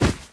Index of /App/sound/monster/wild_boar_god
drop_2.wav